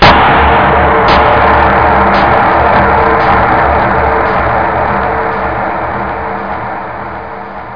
1 channel
fire.mp3